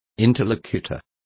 Complete with pronunciation of the translation of interlocutor.